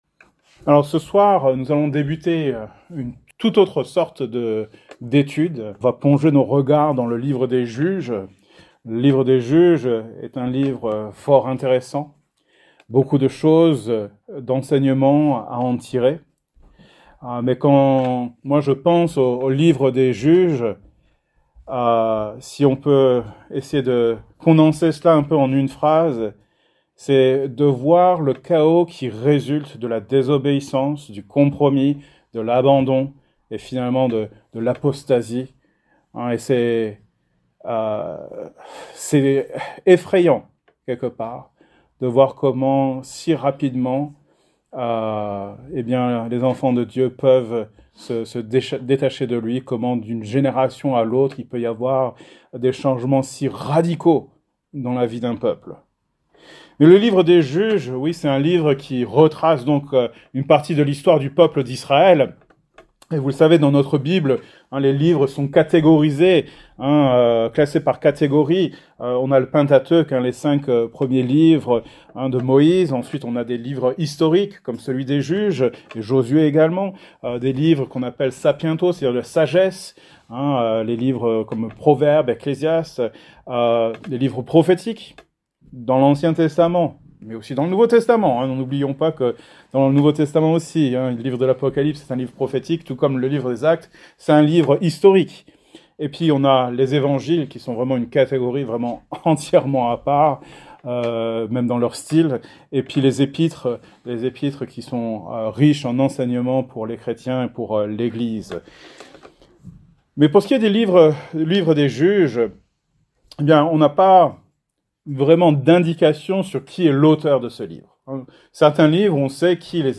Genre: Etude Biblique